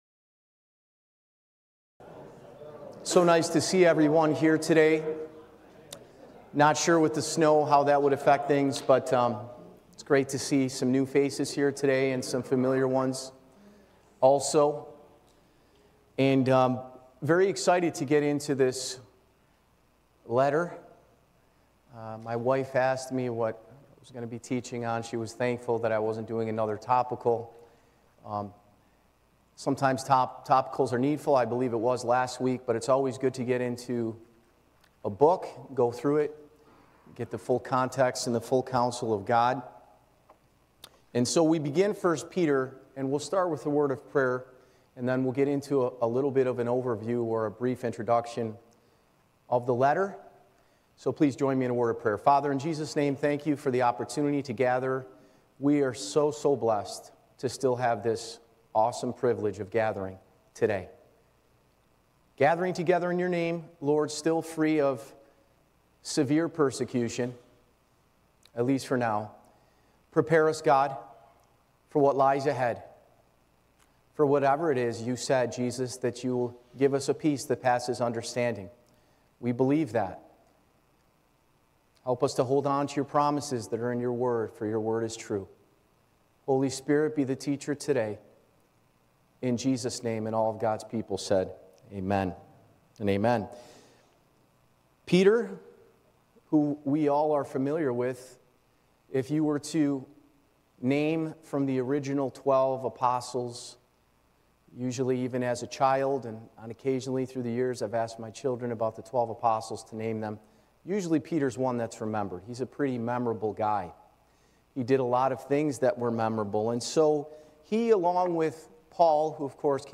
Church Location: Spencerport Bible Church